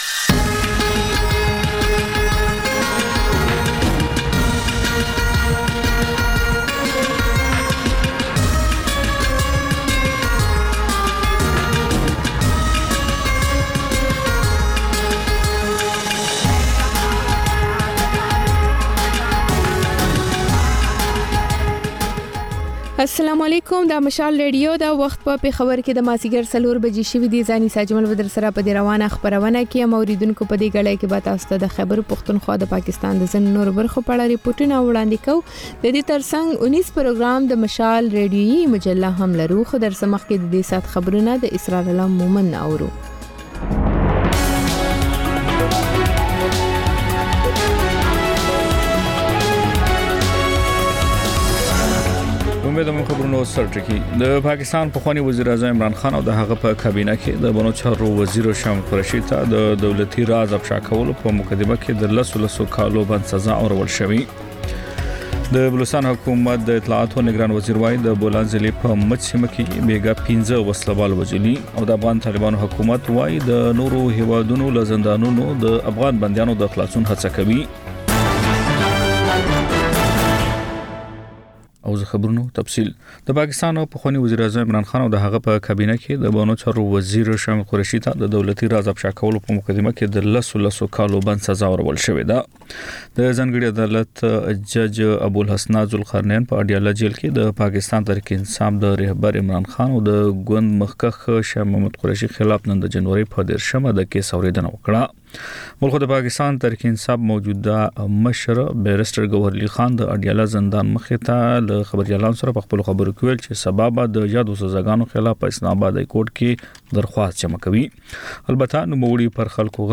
د خپرونې پیل له خبرونو کېږي، ورسره اوونیزه خپرونه/خپرونې هم خپرېږي.